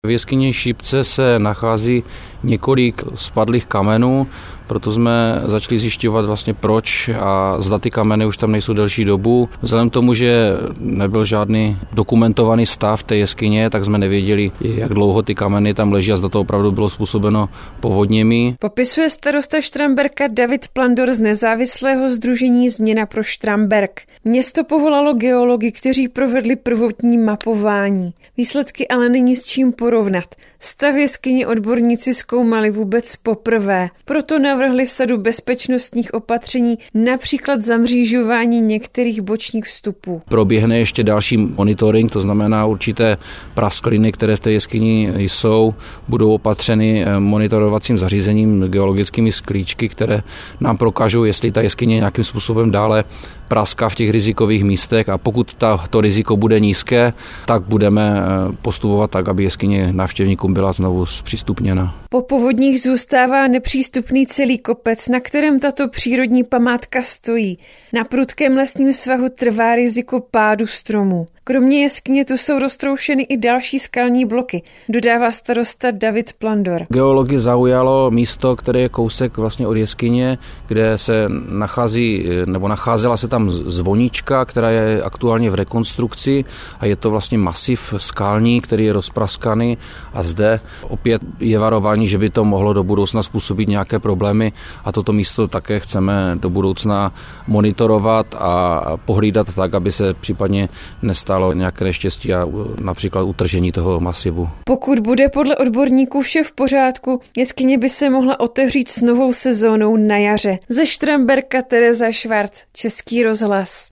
Zprávy ČRo Ostrava: Štramberská jeskyně Šipka je zavřená, z klenby spadlo několik kamenů - 05.01.2025